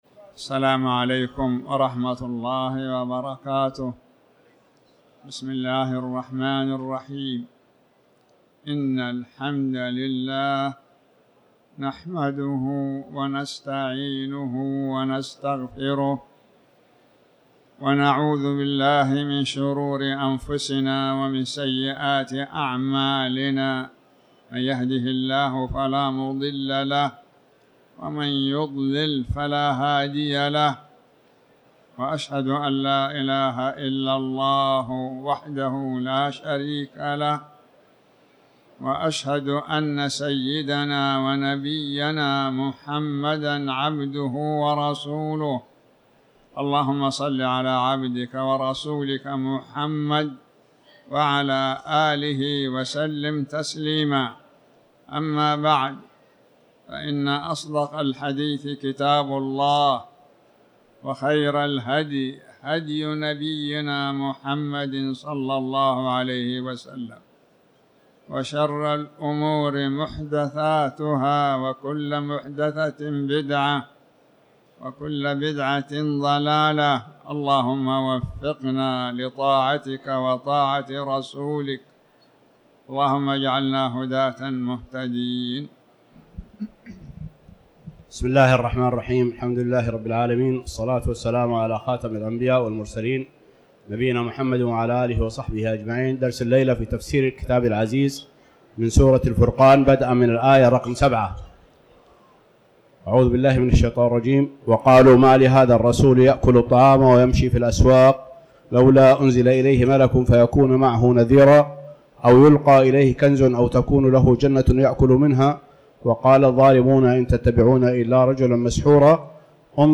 تاريخ النشر ٢٢ جمادى الآخرة ١٤٤٠ هـ المكان: المسجد الحرام الشيخ